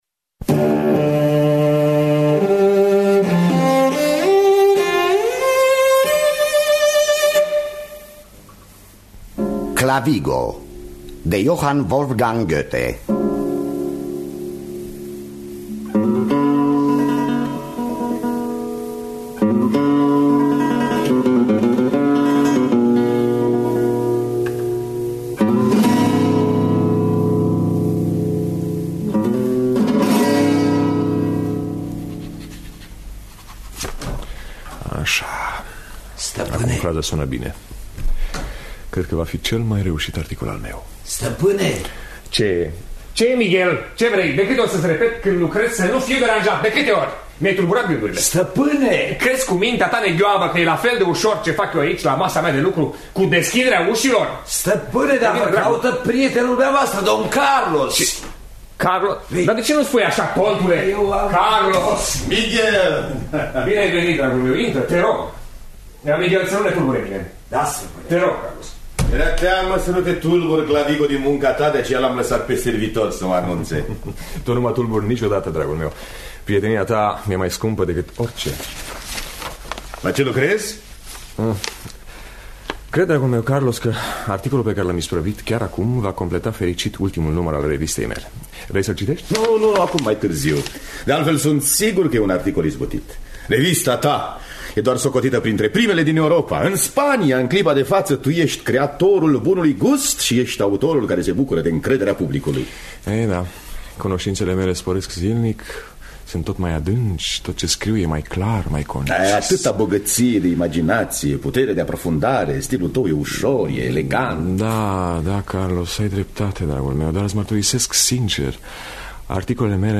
Înregistrare din anul 1984.